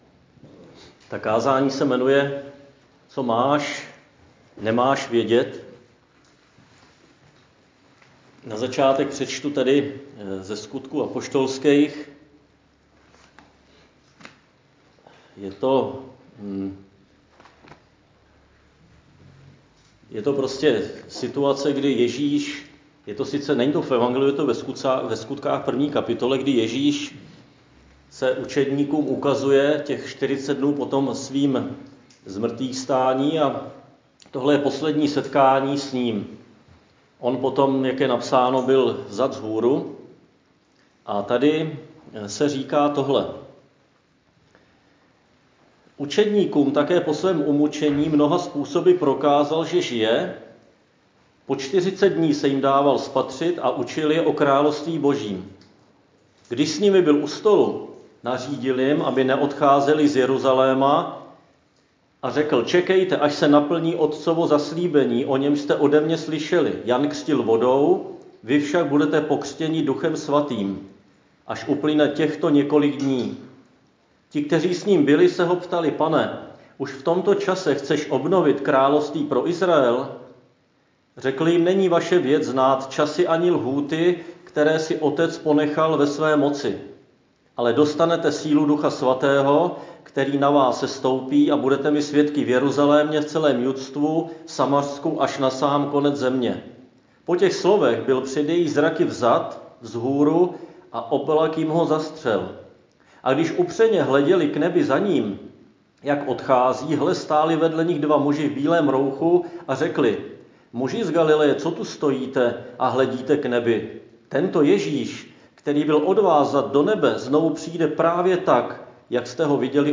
Křesťanské společenství Jičín - Kázání 25.4.2021